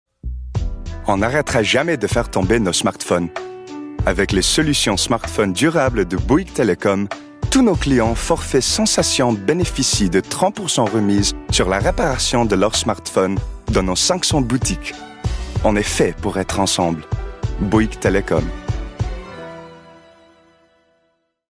French language sample
European ● French Adult